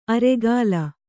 ♪ aregāla